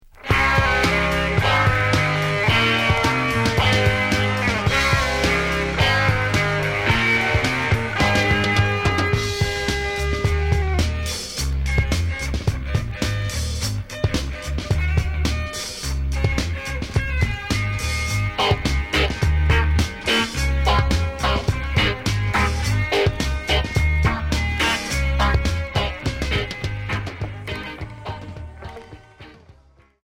Punk rock Premier 45t retour à l'accueil